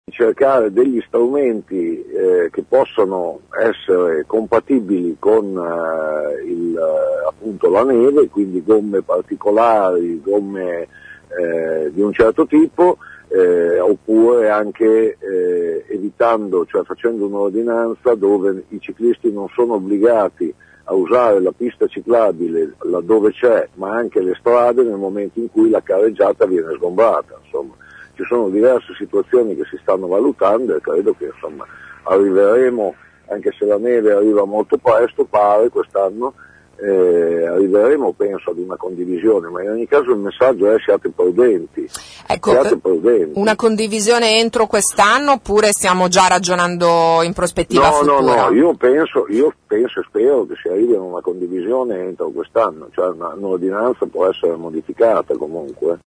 L’assessore Riccardo Malagoli ai nostri microfoni ha confermato che Bologna è l’unica città in Italia ad avere questa ordinanza che ha provocato immediatamente la reazione delle associazioni dei ciclisti.